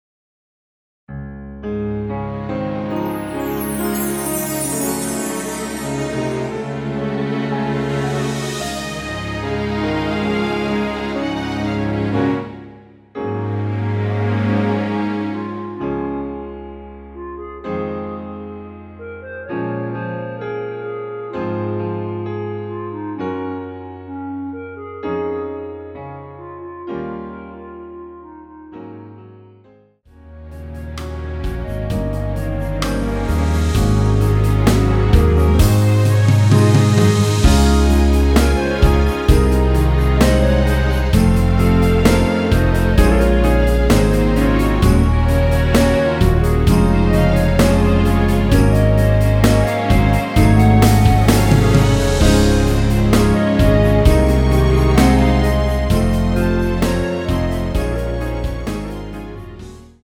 원키에서(-3)내린 멜로디 포함된 MR입니다.(미리듣기 확인)
Db
앞부분30초, 뒷부분30초씩 편집해서 올려 드리고 있습니다.
중간에 음이 끈어지고 다시 나오는 이유는